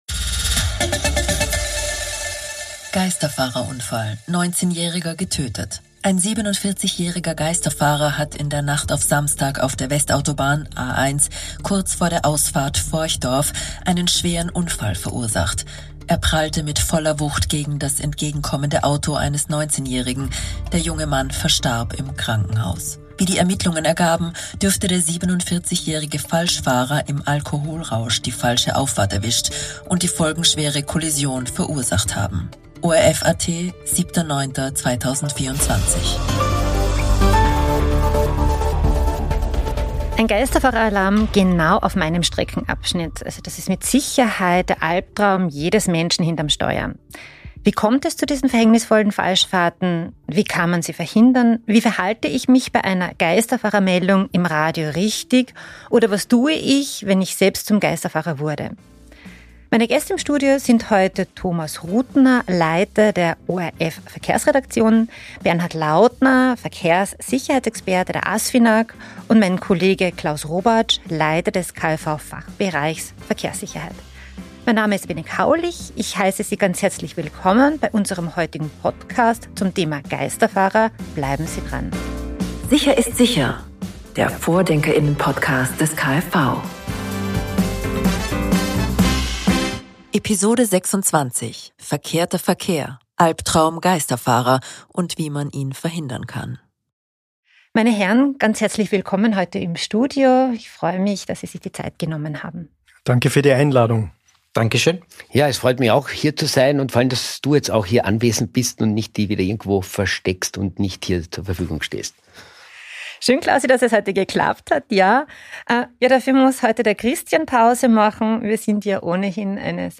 in einem spannenden Podcast-Gespräch Daten